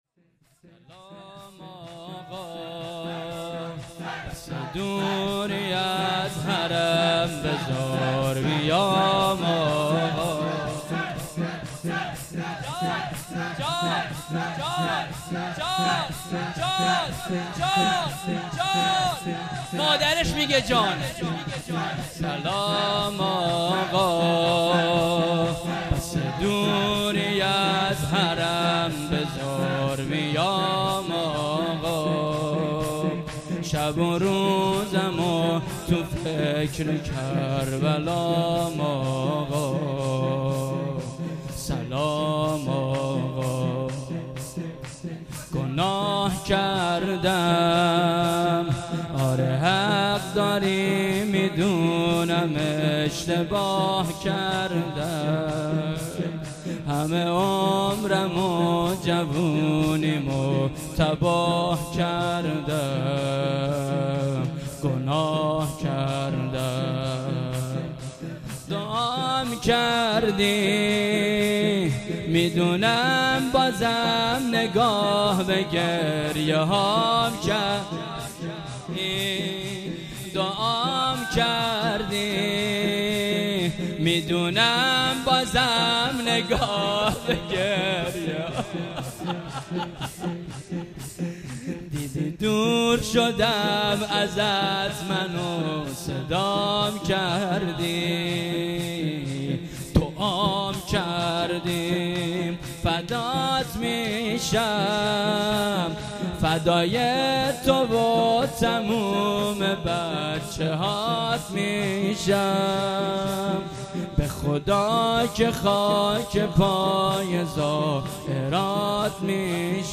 شور
مراسم شهادت حضرت زهرا (س) و هفتم سپهبد شهید قاسم سلیمانی | فاطمیه اول (شب دوم)